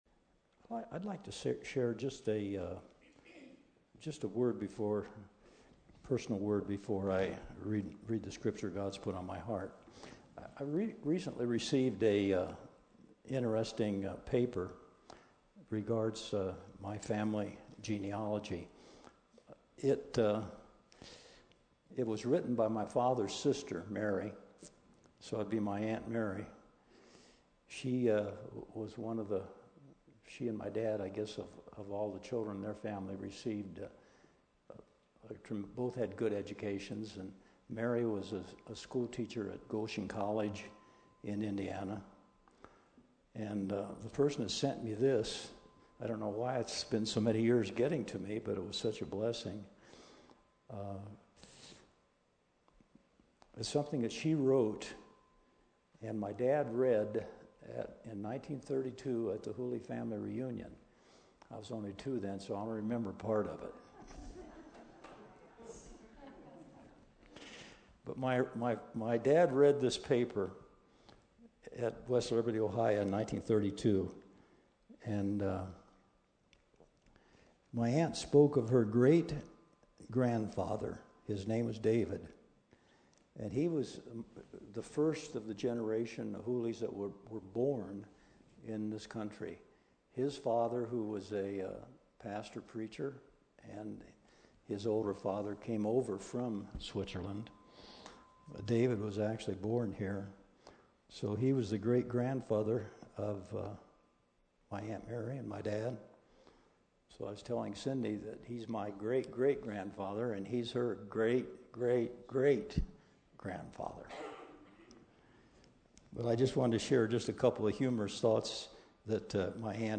Testimonies, teachings, sharing.